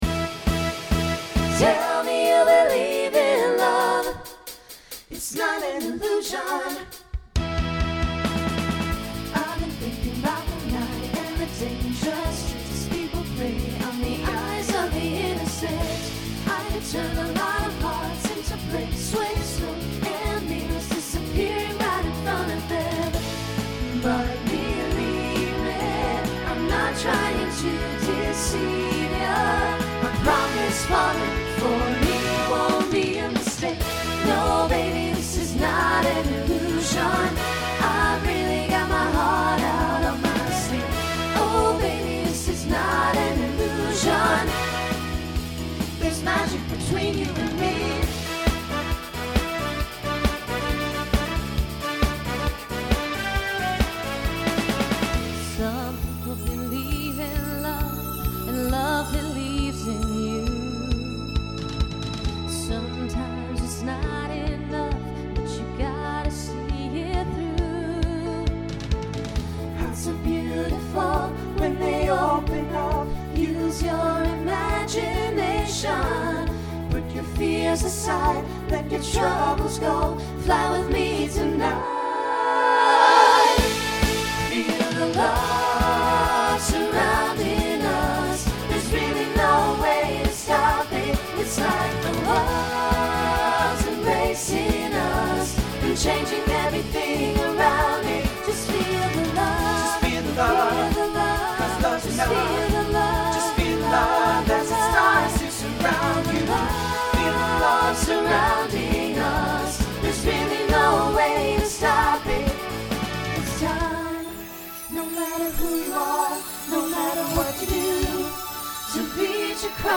New SSA Voicing for 2026.
SSA Instrumental combo Genre Pop/Dance